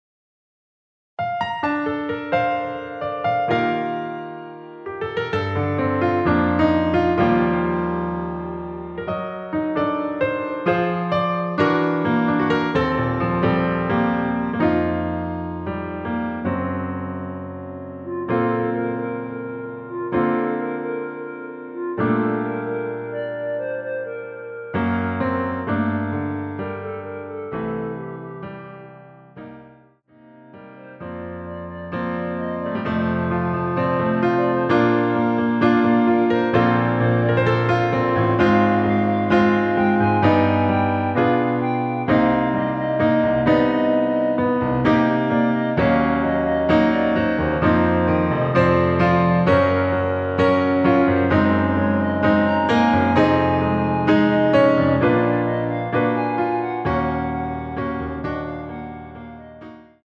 원키 멜로디 포함된 MR 입니다.
Bb
앞부분30초, 뒷부분30초씩 편집해서 올려 드리고 있습니다.
중간에 음이 끈어지고 다시 나오는 이유는